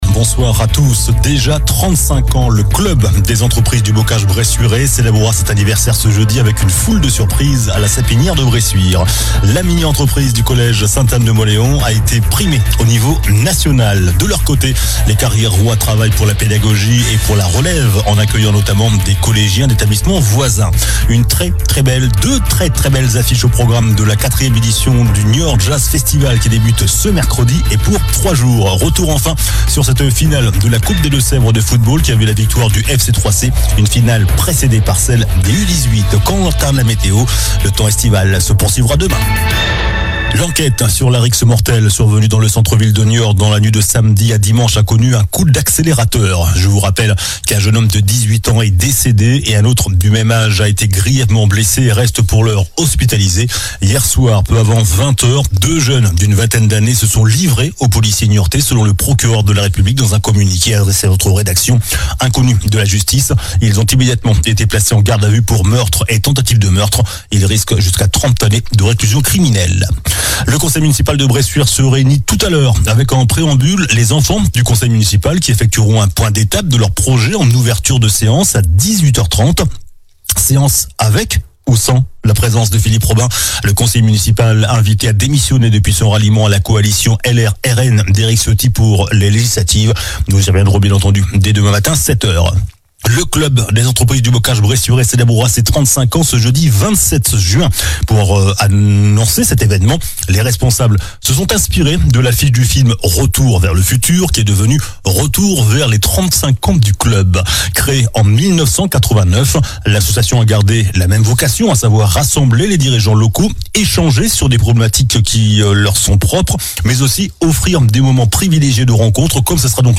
JOURNAL DU LUNDI 24 JUIN ( SOIR )